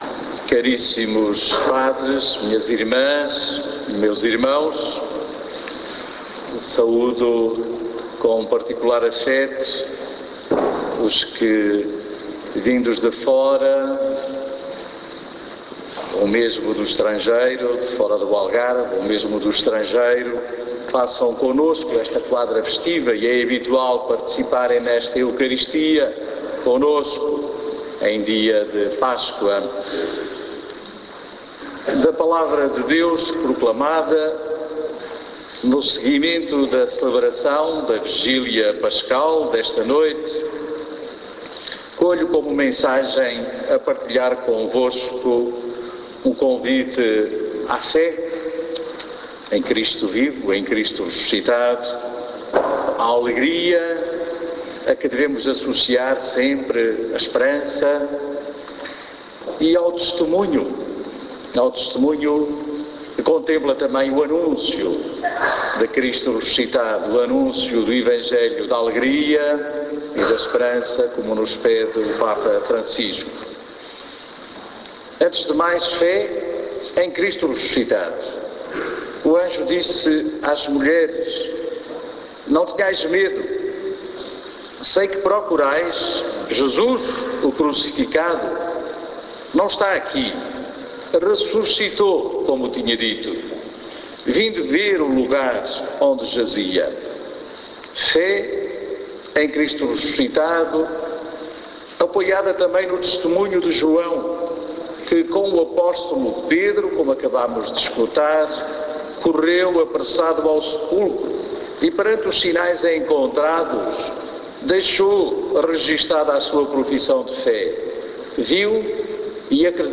Homilia_missa_domingo_pascoa_2014.mp3